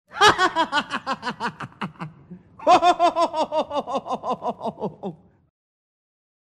Klingelton Man Heartily Laughing
Kategorien Lustige